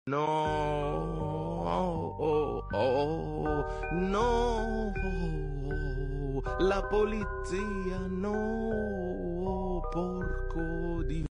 Noooo le police sound effect sound effects free download